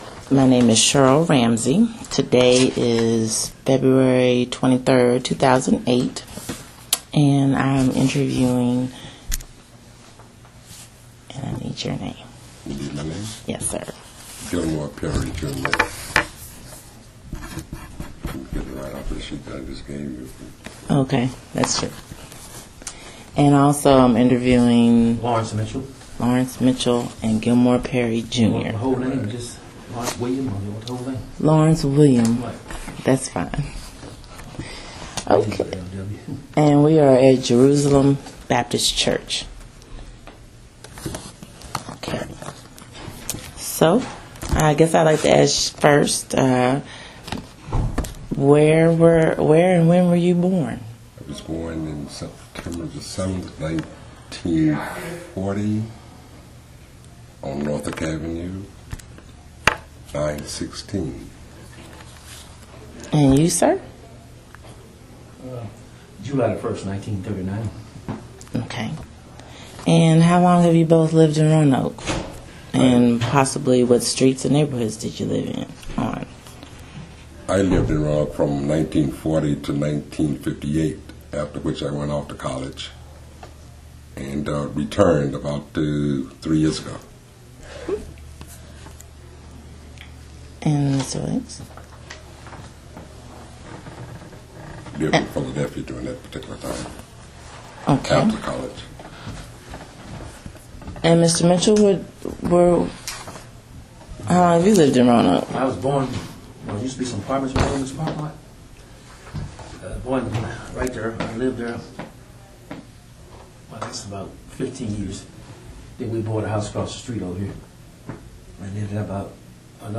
Location: Jerusalem Baptist Church